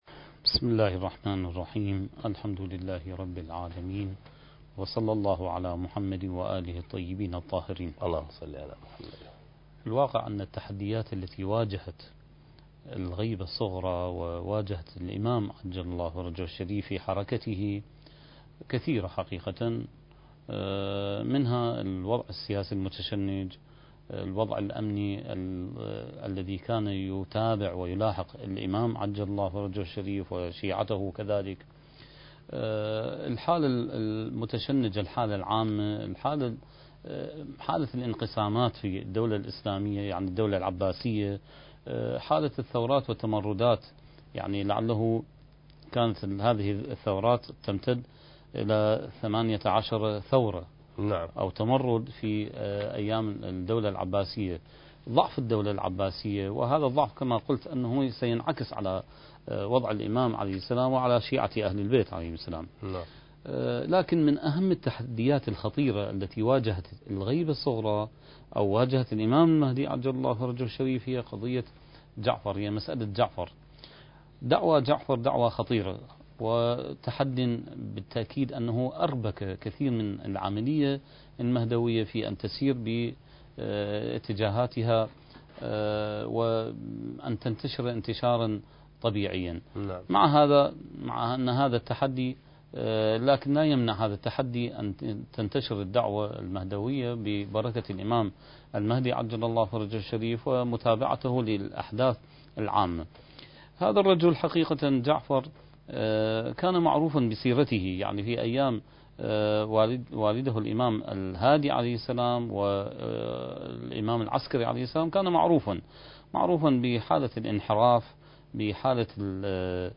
سلسلة محاضرات: بداية الغيبة الصغرى (2) برنامج المهدي وعد الله انتاج: قناة كربلاء الفضائية